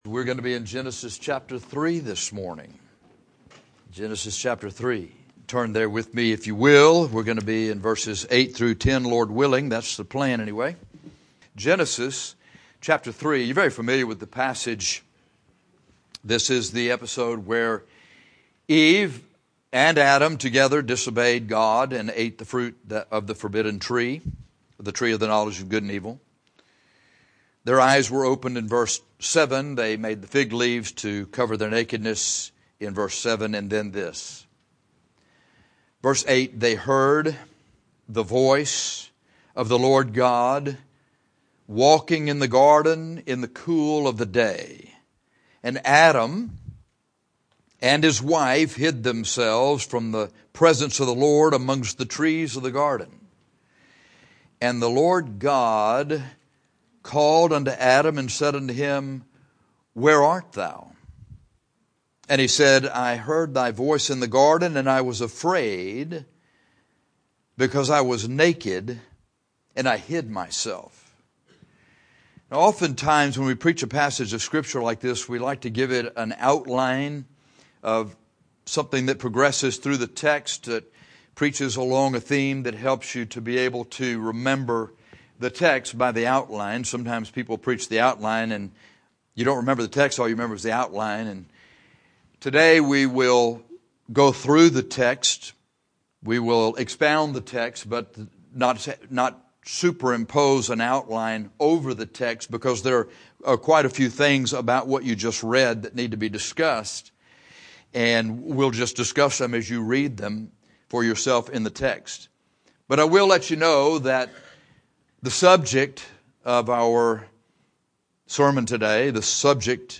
Hiding from the presence of the Lord is a message that describes what most people do when they sin.